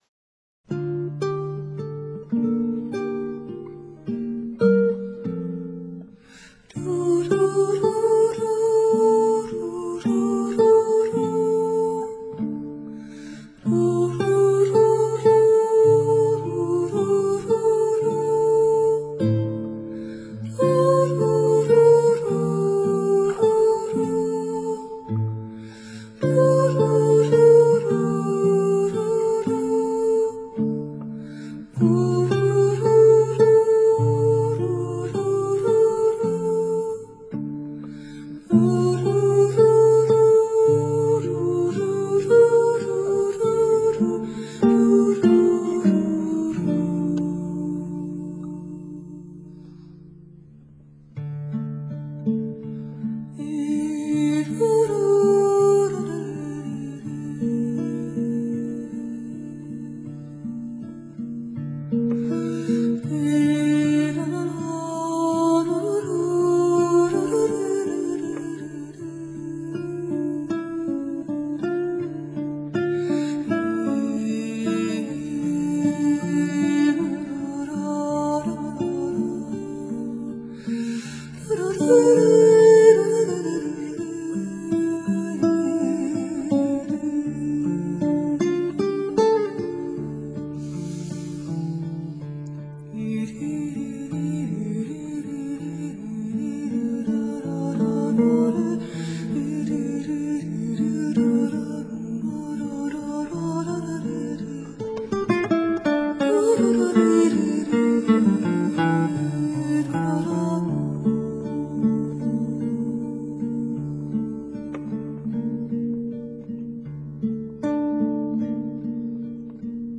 简单不过的钢琴、贝司与几件打击乐器，还有她天赋的一条好嗓子，被她组合成致命的武器，对准了人们脆弱的心坎，一击即中！
精彩过瘾的录音还包括极自然的空间效果，晶莹光滑的钢琴以及弹性十足的贝司。